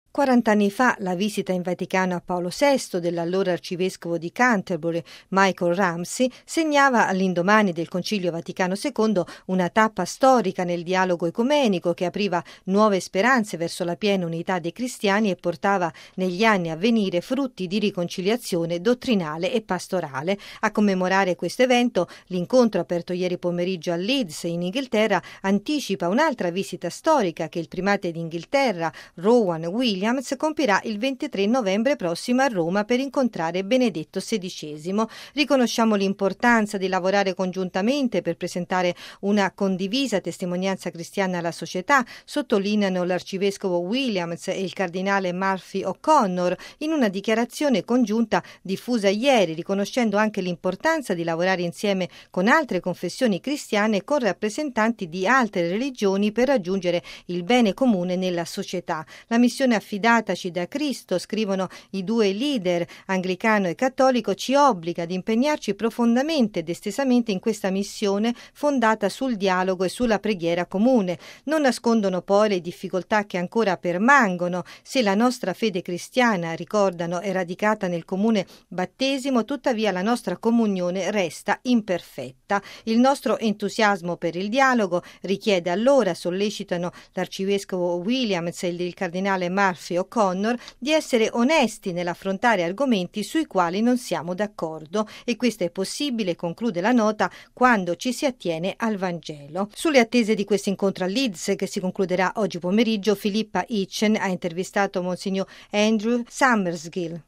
(15 novembre 2006) Giornata storica nel cammino ecumenico: per la prima volta riuniti insieme a Leeds i vescovi cattolici d’Inghilterra e Galles e quelli anglicani, guidati rispettivamente dal Primate d’Inghiterra Rowan Williams, arcivescovo di Canterbury, e dal cardinale Cormac Murphy-O’Connor, arcivescovo di Westminster. Il servizio